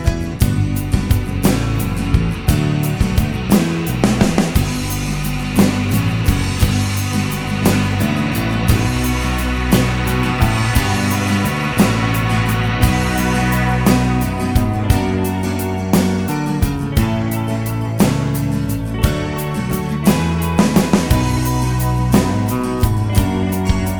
no Backing Vocals Indie / Alternative 3:45 Buy £1.50